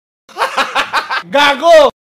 hahaha gago Meme Sound sound effects free download